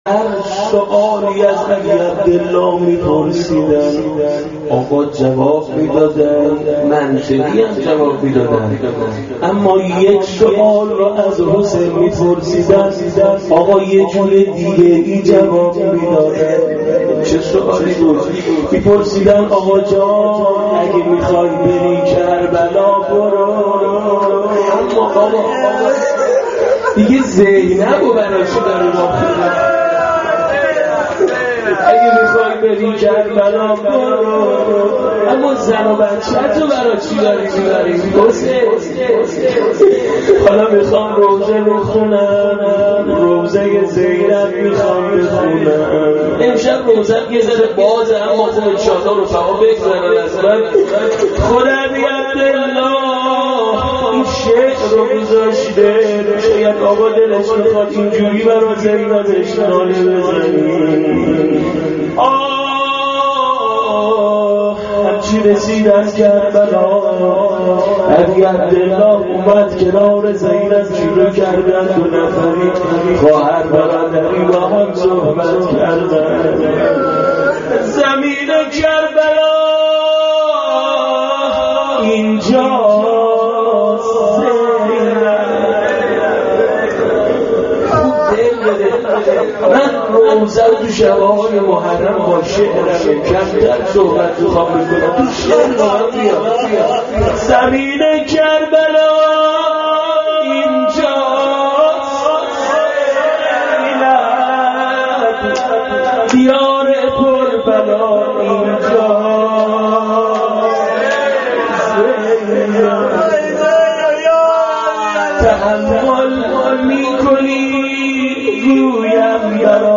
روضه-ورود-به-کربلا-و-روضه-حضرت-زینب.mp3